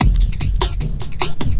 10 LOOP01 -R.wav